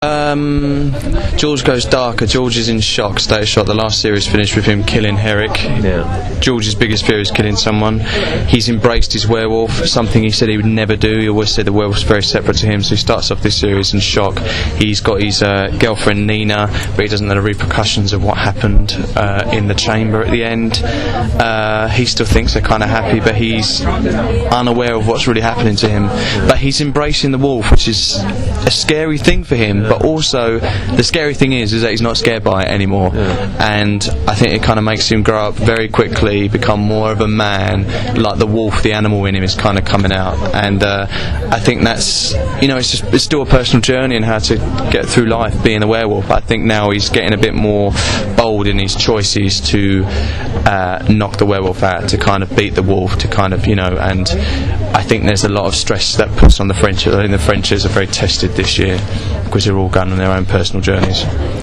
In the hours before the preview screening of episode one, I interviewed Russell (werewolf George), Aidan Turner (vampire Mitchell) and Lenora Crichlow (ghost Annie).